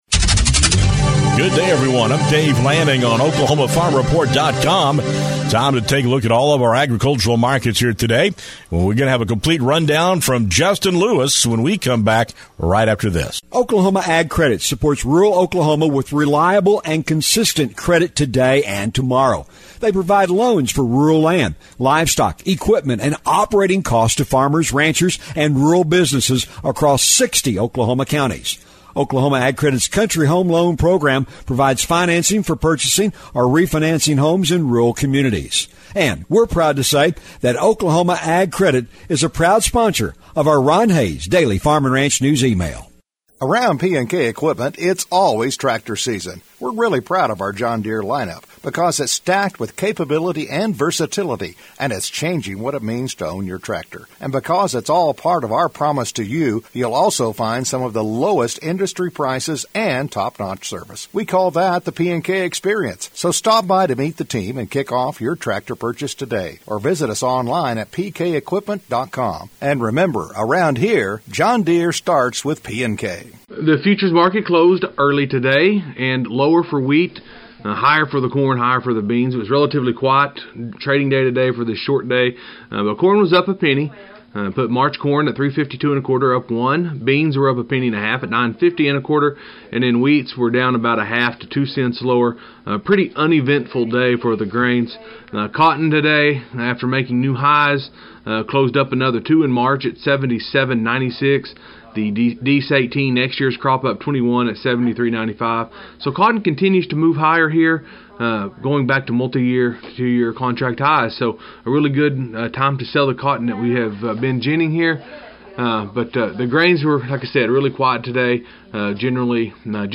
Agricultural News